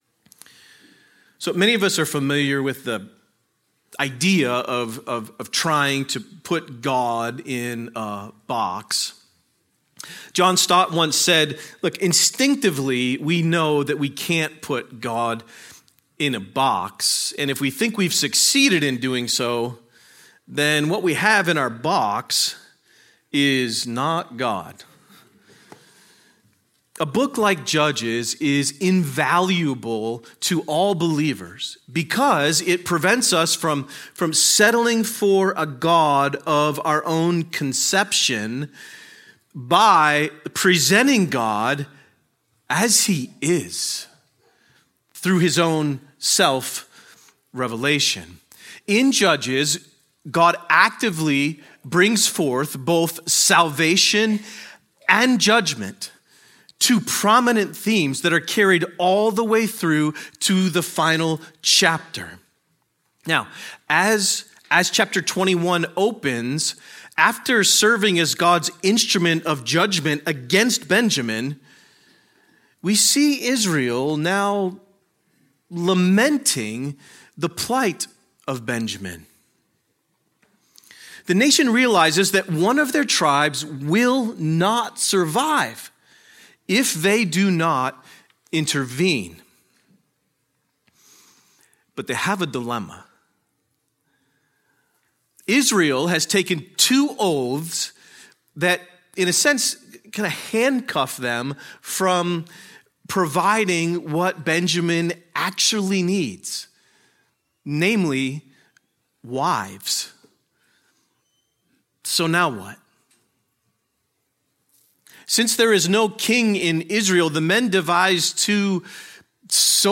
A sermon on Judges 21